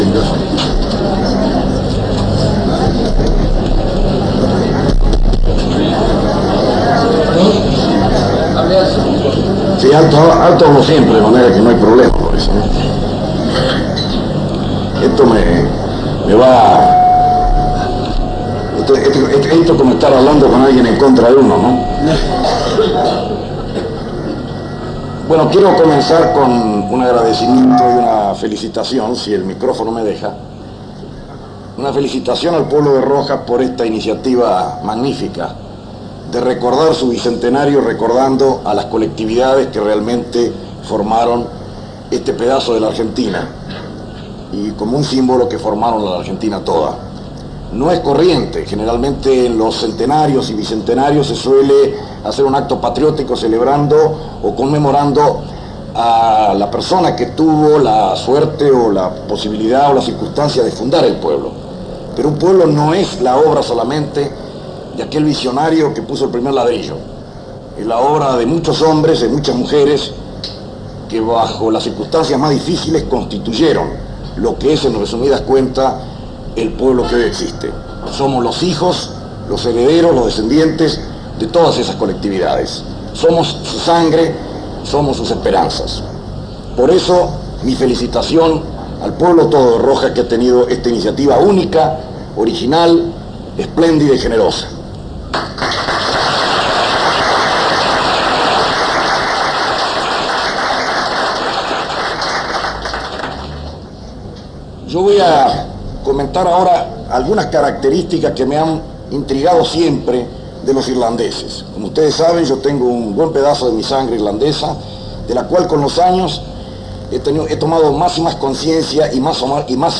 Conferencia realizada en el Hotel Victoria, en la ciudad de Rojas, provincia de Buenos Aires, con motivo de la celebración del bicentenario de la ciudad.